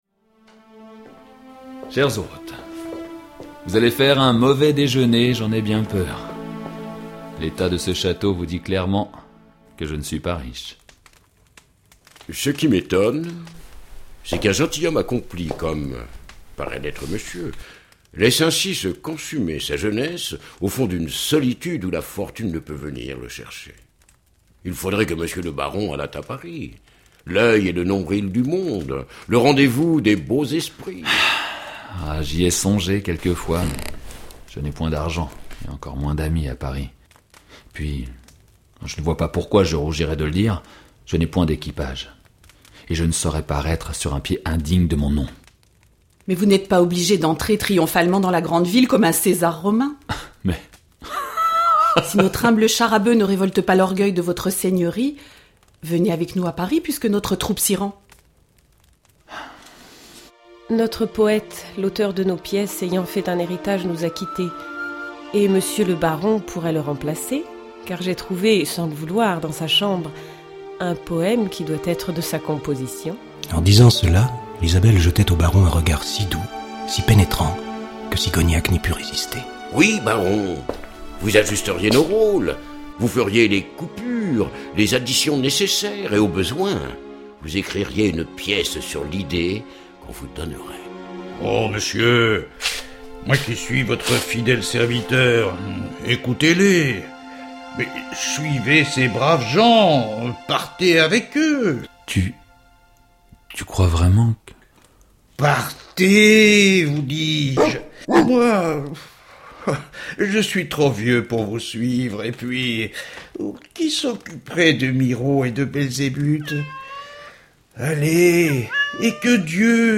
Diffusion distribution ebook et livre audio - Catalogue livres numériques